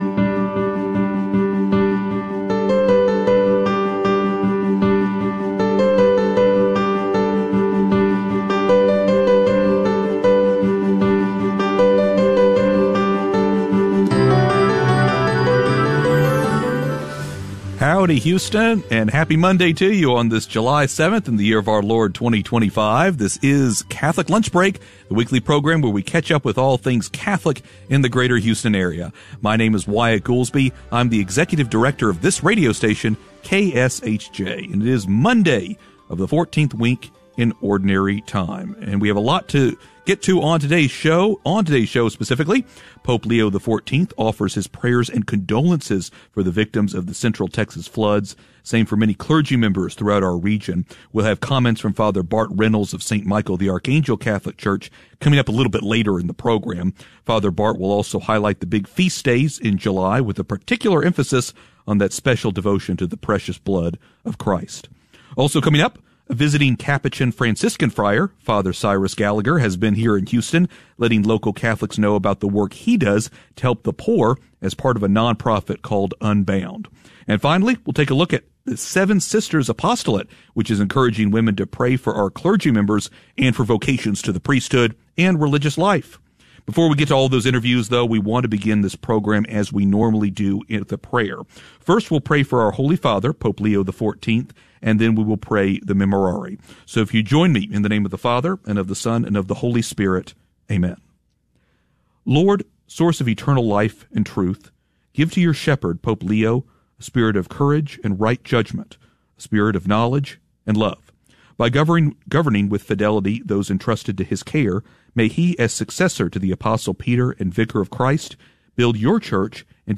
It airs live every Monday at noon in Houston on AM 1430 KSHJ, and podcasts here for your listening convenience. We’re going to talk to priests from every parish in the archdiocese, Catholics doing amazing things that you haven’t heard of, and you might even learn something about your faith without even trying.